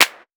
Vermona Clap 07.wav